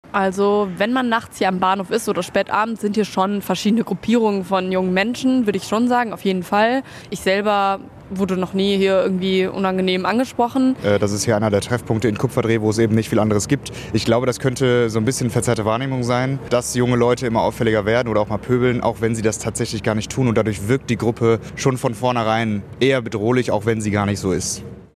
Pendler haben keine Angst am Bahnhof Kupferdreh